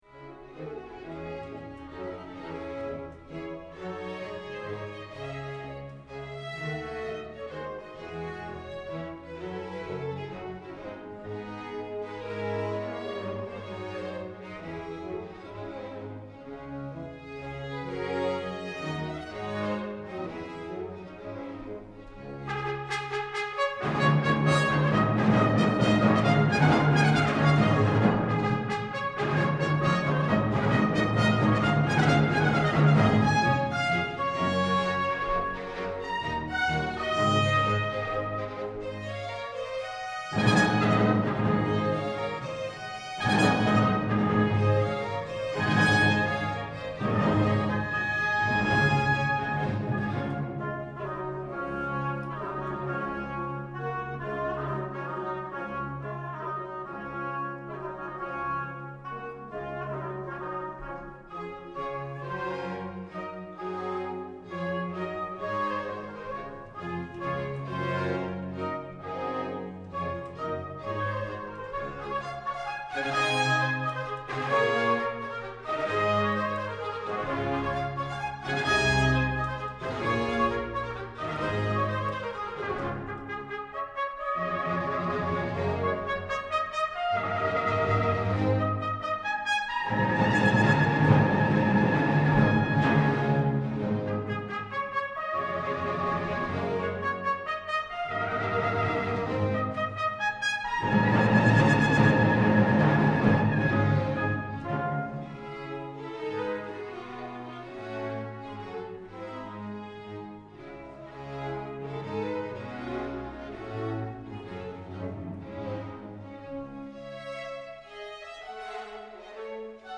Salle Paderewski – Casino de Montbenon